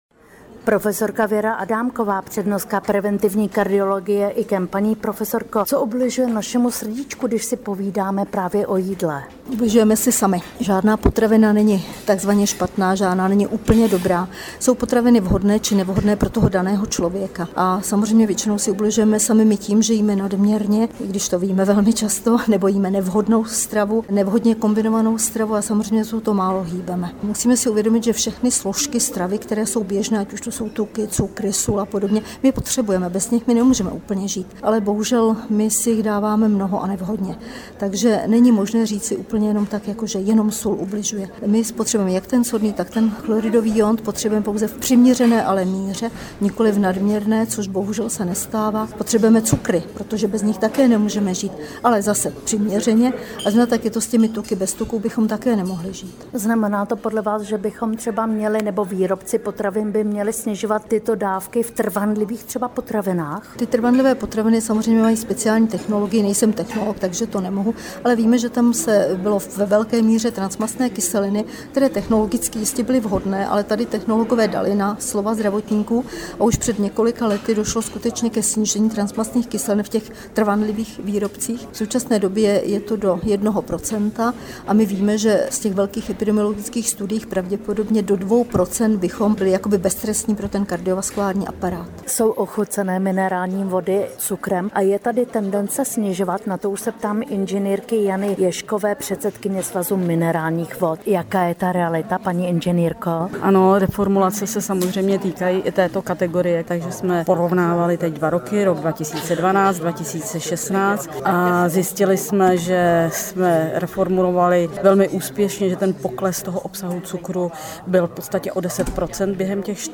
Rozhovor s odborníky o složení potravin na českém trhu
Poslechněte si rozhovor odborníky na toto téma
jídlo-bez-cukru-a-soli-Věra-Adámková.mp3